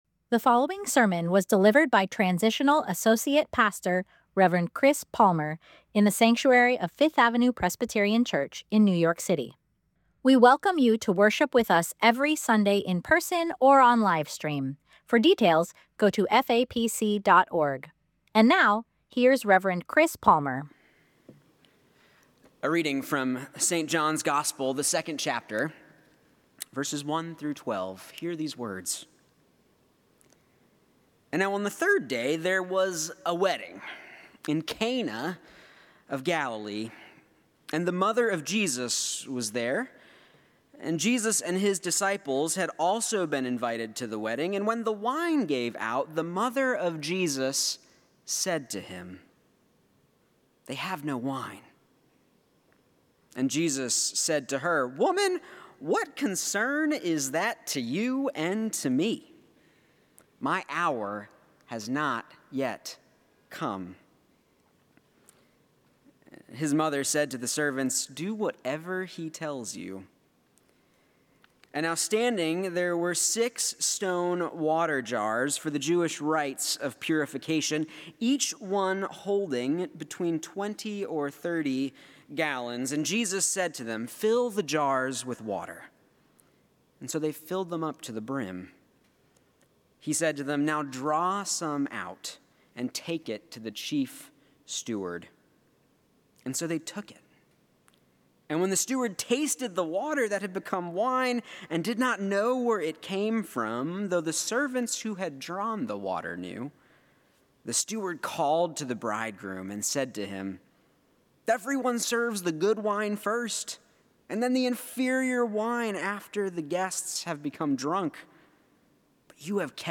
Sermon: “Wrestling a Miracle” Scripture: John 2:1-12 Download sermon audio Order of Worship O n the third day there was a wedding in Cana of Galilee, and the mother of Jesus was there.